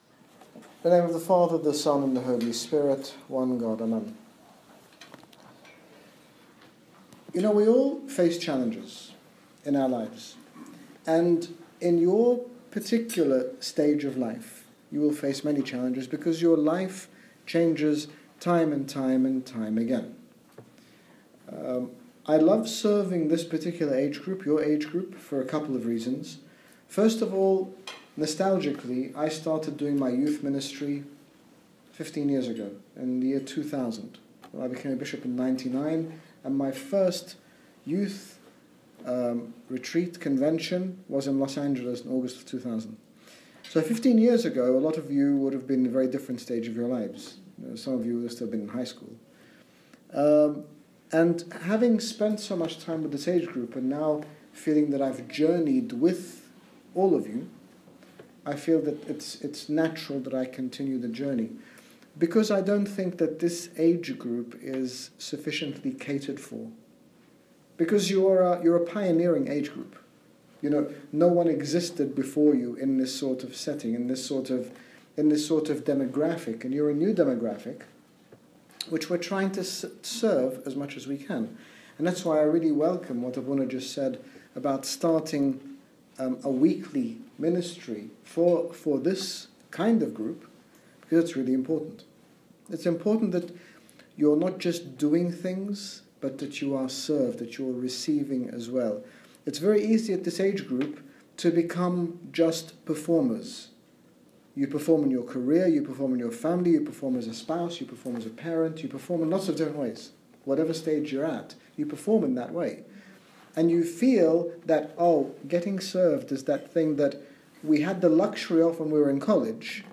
In this talk His Grace Bishop Angaelos, General Bishop of the Coptic Orthodox Church in the United Kingdom, speaks about prioritising our spiritual lives, and being faithful with our time. His Grace speaks about the conflict we face in trying to balance our social, work, and family time along with other commitments. Download Audio Read more about Prioritisation - Talk 1 - Pittsburg Agape 2015 Series